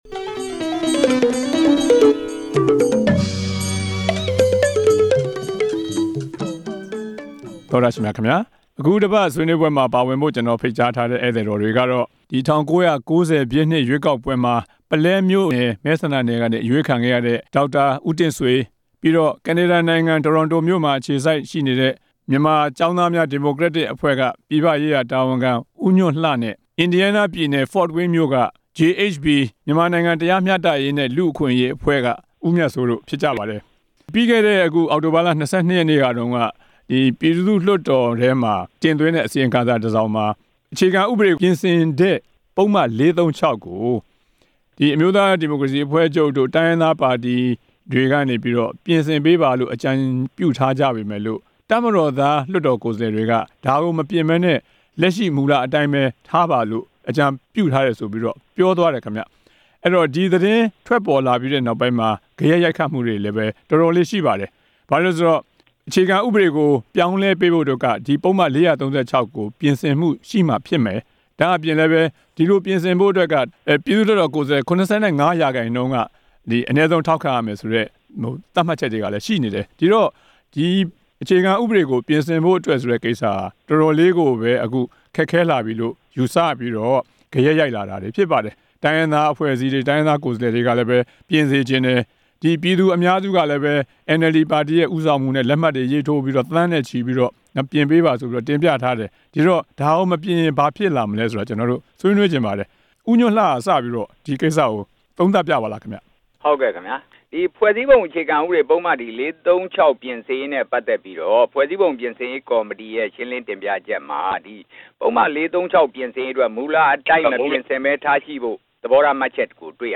တပ်ကိုယ်စားလှယ်တွေရဲ့ ပုဒ်မ ၄၃၆ မပြင်ဆင်လိုတဲ့အပေါ် ဆွေးနွေးချက်